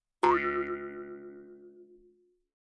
Mouth harp vol. 1 » Mouth harp 9 lower formant staccato down
描述：口琴（通常被称为“犹太人的竖琴”）调到C＃。 用RØDENT2A录制。
Tag: 竖琴 曲调 Mouthharp 共振峰 仪器 传统的 jewsharp 共振峰 弗利